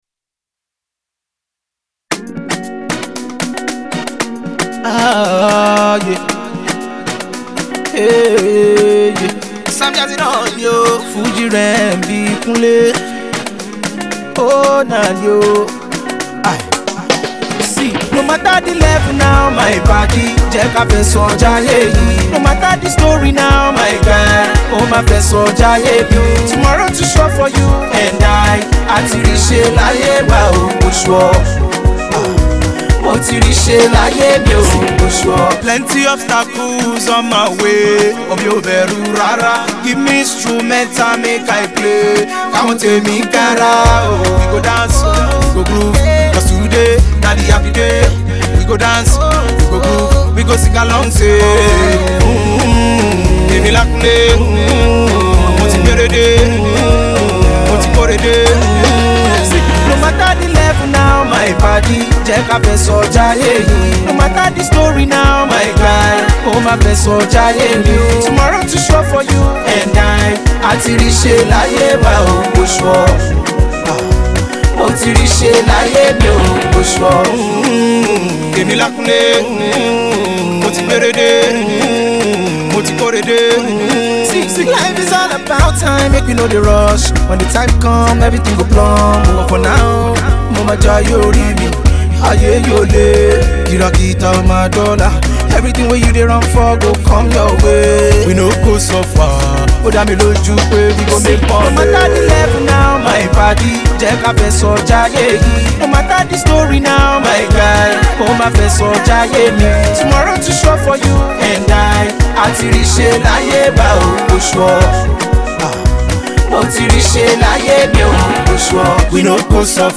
It is a prayer song which speaks possibilities.